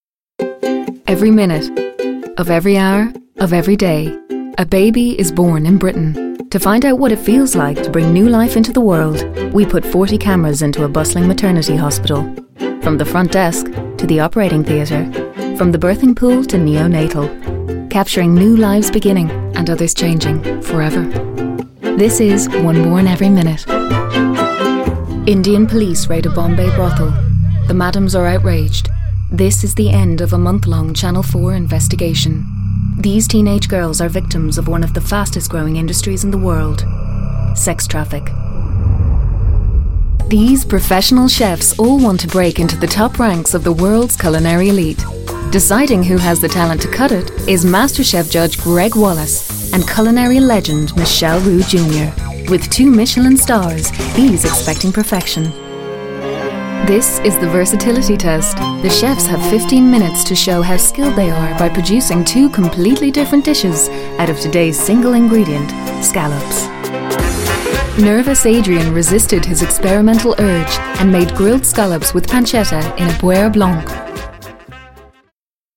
Inglês (Internacional)
Demonstração Comercial
Rode NT2 + Kaotica Eyeball